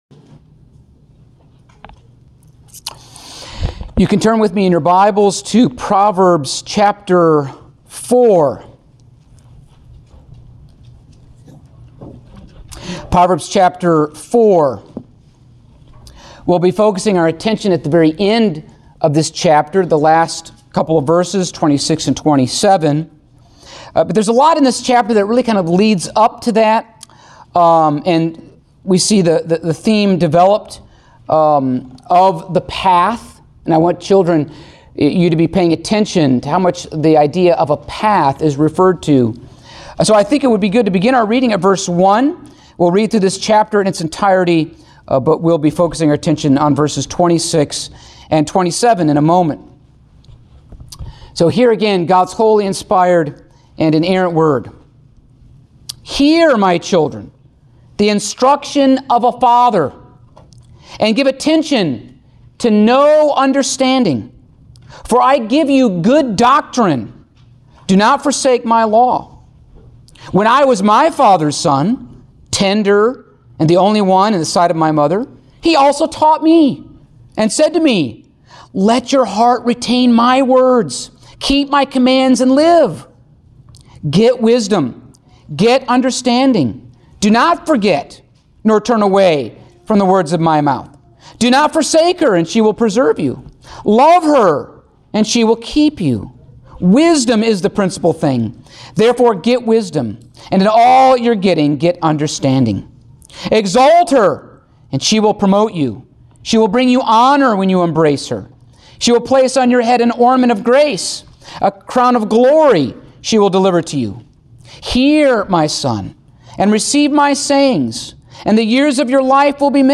Service Type: Sunday Morning Topics: The Eyes Ears Mouth Hands and Feet of Proverbs « The Hands